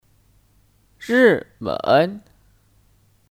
日本 (Rìběn 日本)